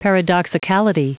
Prononciation du mot paradoxicality en anglais (fichier audio)
Prononciation du mot : paradoxicality